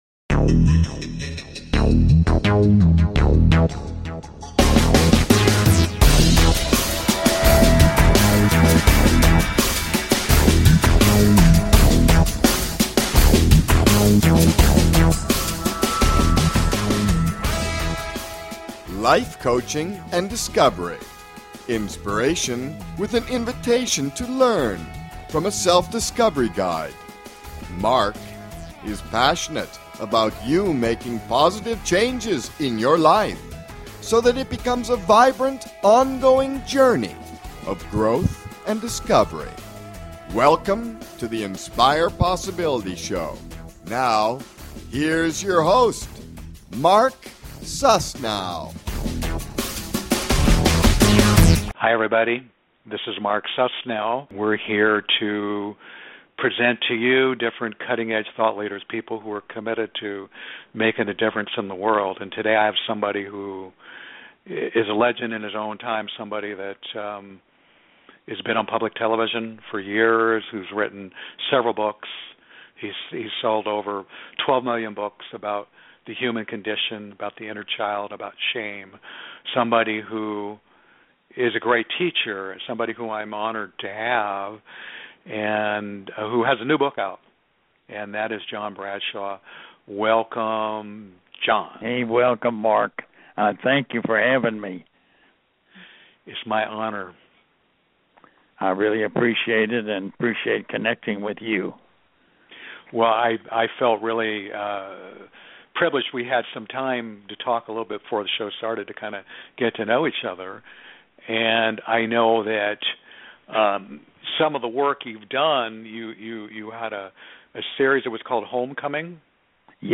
Talk Show Episode
This is one of the last interviews of the legendary John Bradshaw.